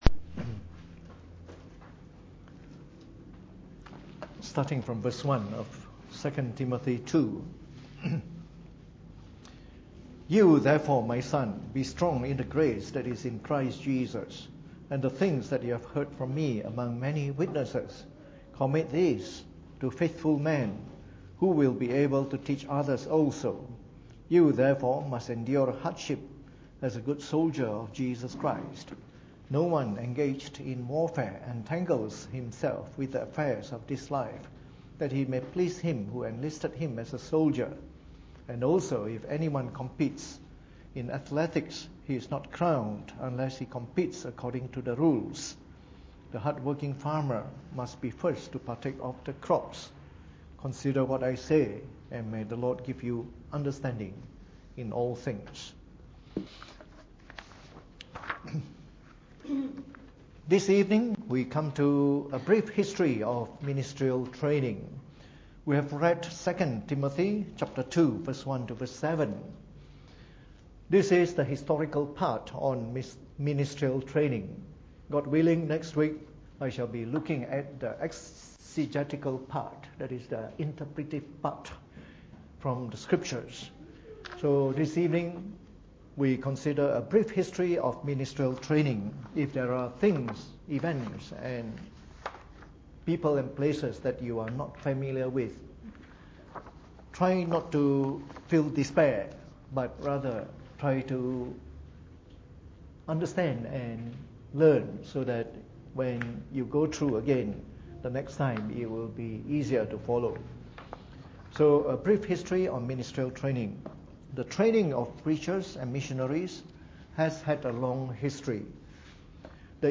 Preached on the 15th of November 2017 during the Bible Study.